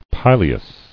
[pi·le·us]